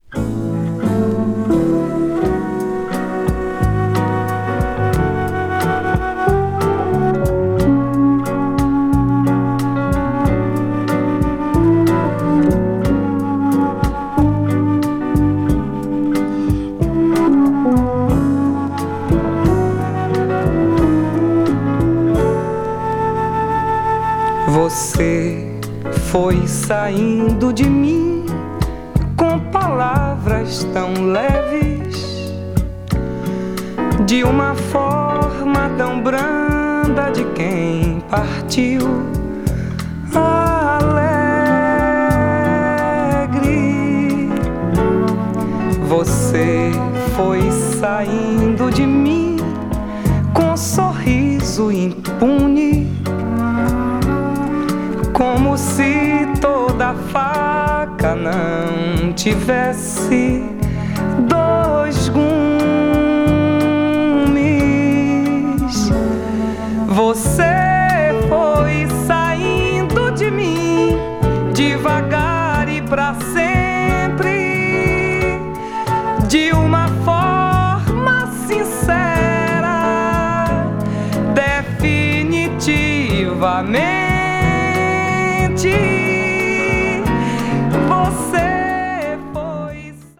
フルートの有機的な音色とアコースティック・ピアノが穏やかに流れる
boss nova   brazil   mellow groove   mpb   pop   world music